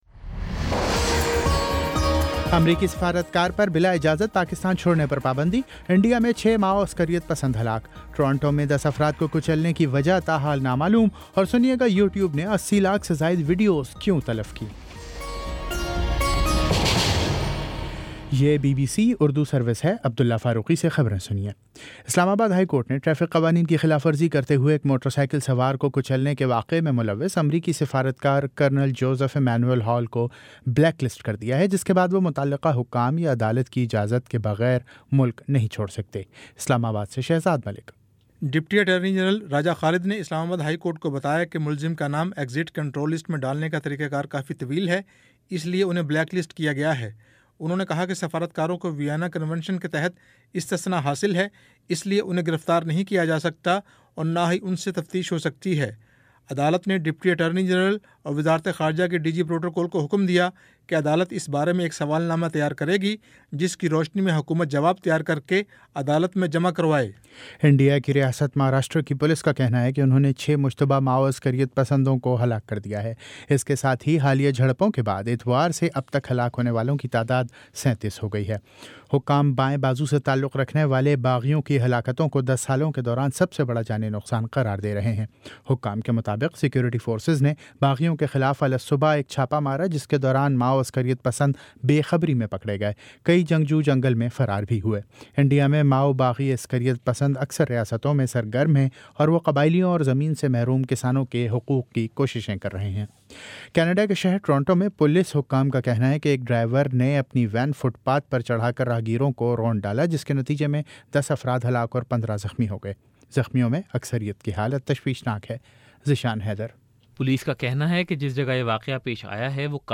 اپریل 24 : شام پانچ بجے کا نیوز بُلیٹن
دس منٹ کا نیوز بُلیٹن روزانہ پاکستانی وقت کے مطابق شام 5 بجے، 6 بجے اور پھر 7 بجے۔